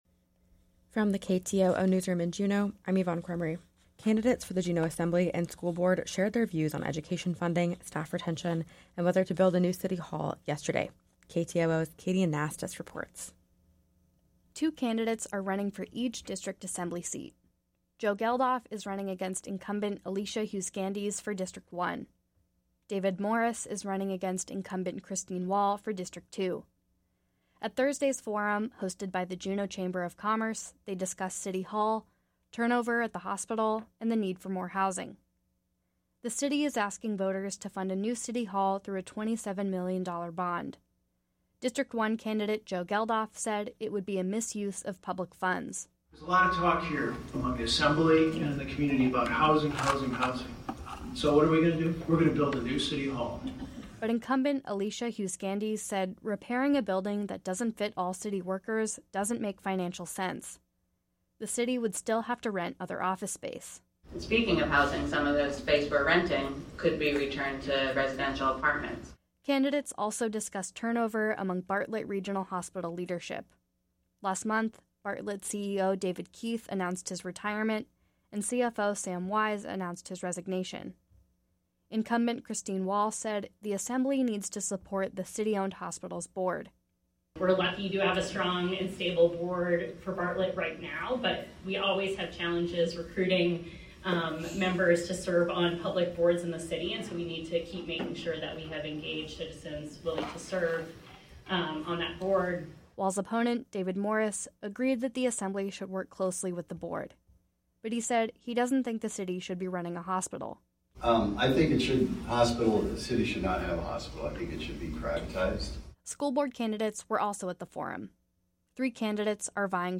Newscast – Friday, Sept. 8, 2023